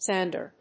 サンダー